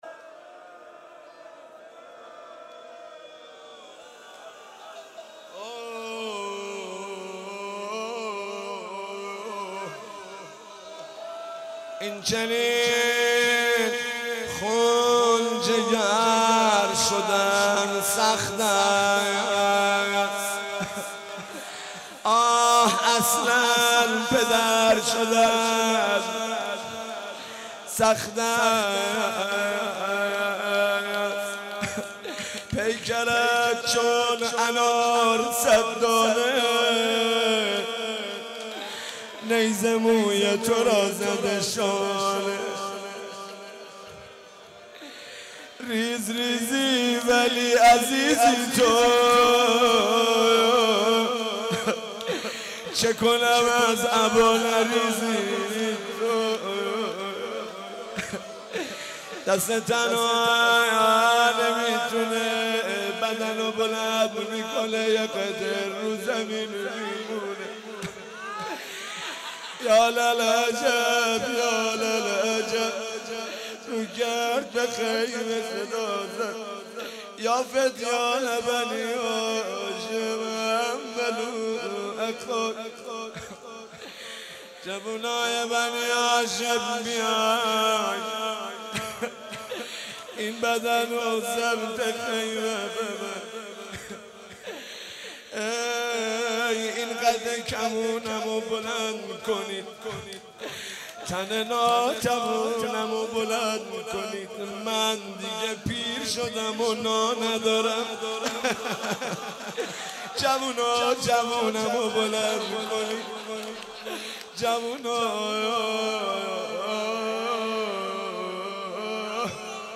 روضه حضرت علی اکبر